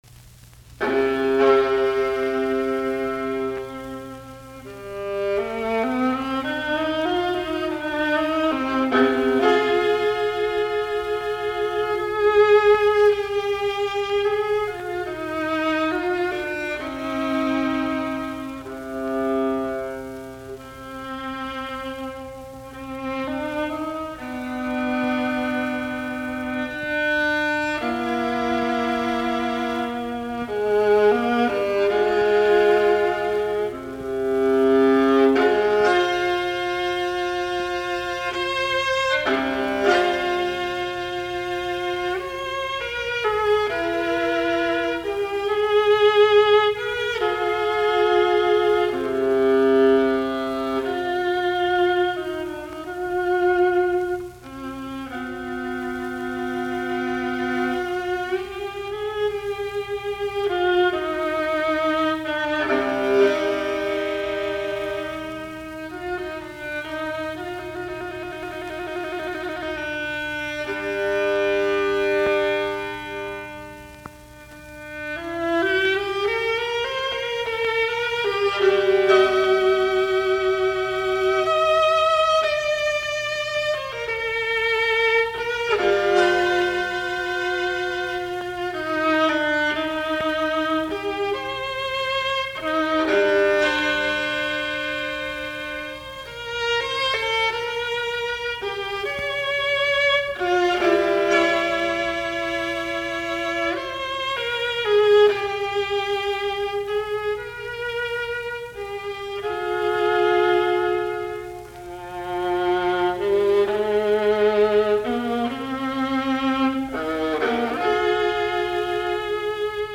alttoviulu